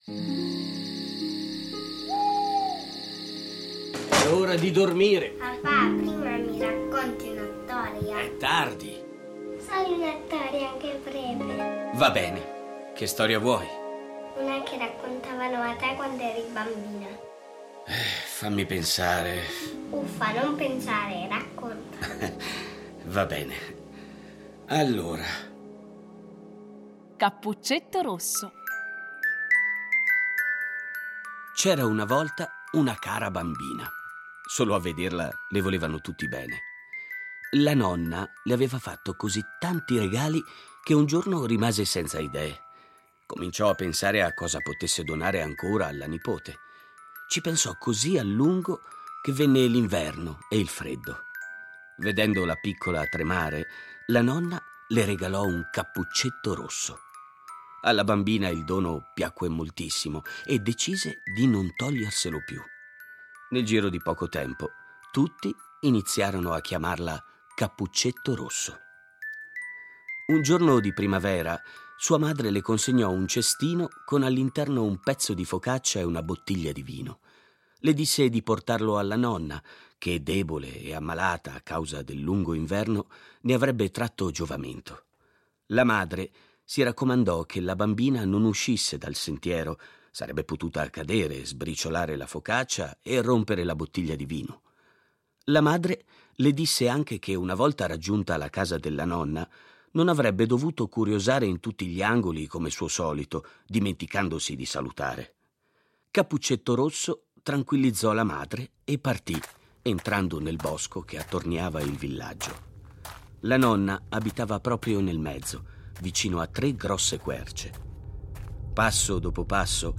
Fiabe
A partire dai testi originali un adattamento radiofonico per fare vivere ai bambini storie conosciute, ma un po’ dimenticate.